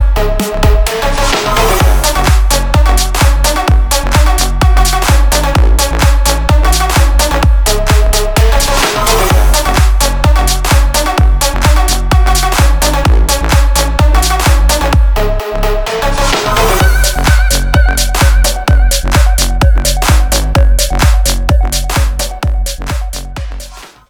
• Качество: 320, Stereo
громкие
атмосферные
заводные
Electronic
EDM
future house